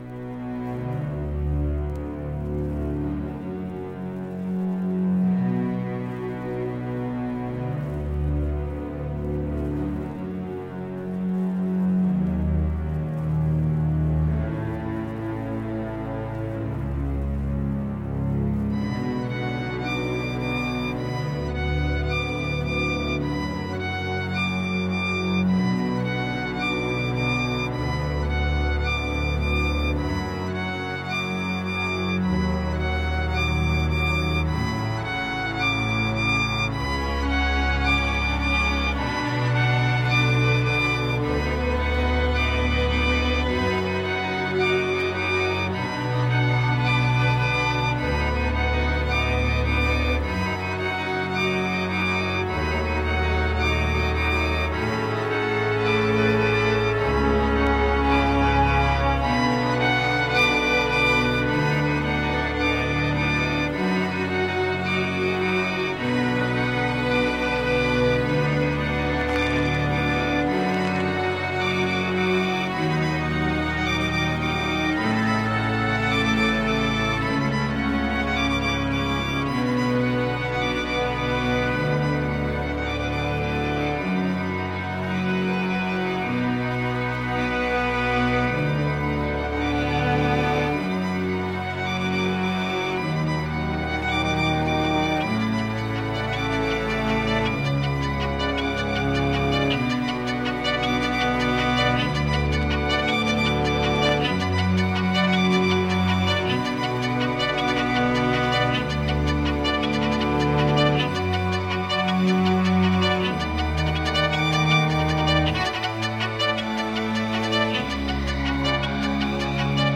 Amen breaks como nunca os ouvimos